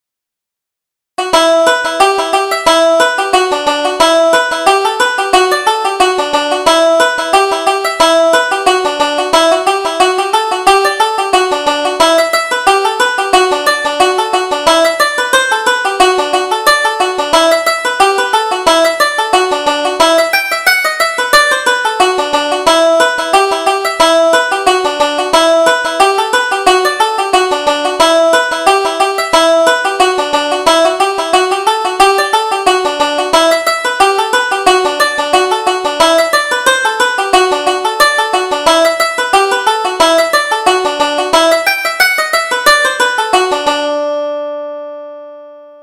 Reel: The Man with the Money